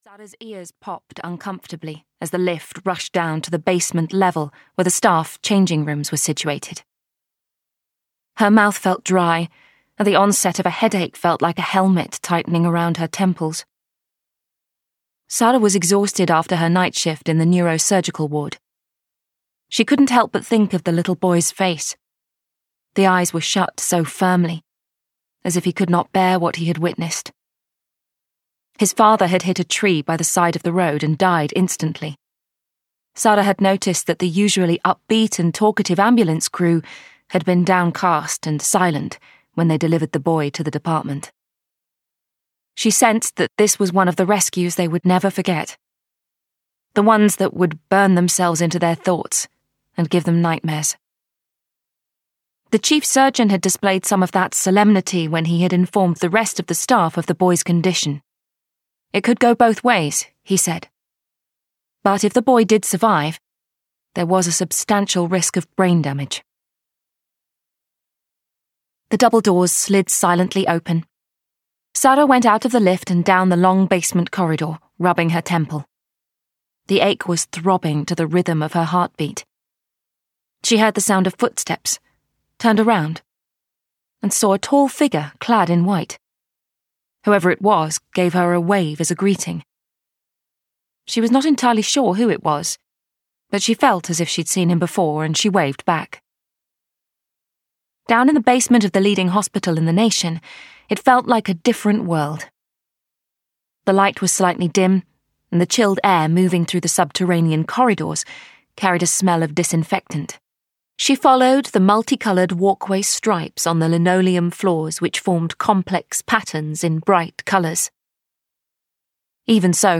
Ukázka z knihy